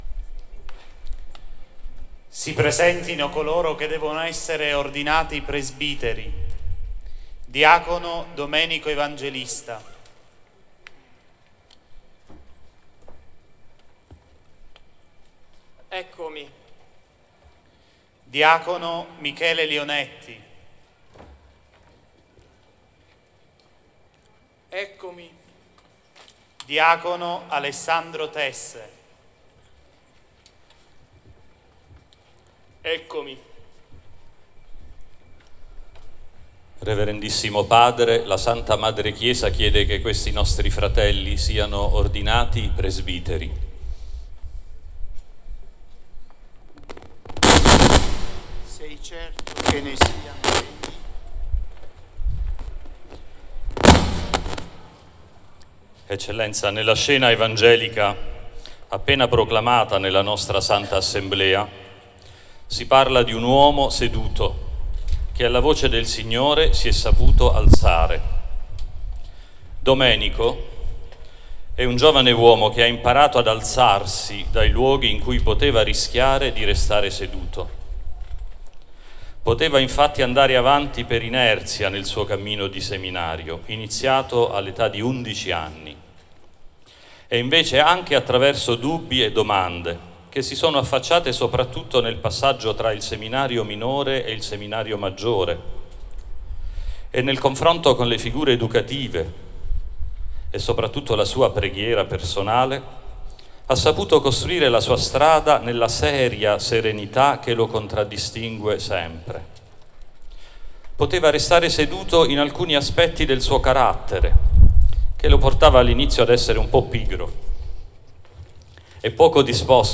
Ordinazione Sacerdotale (foto e audio)
OrdinazioneSac_presentazione.mp3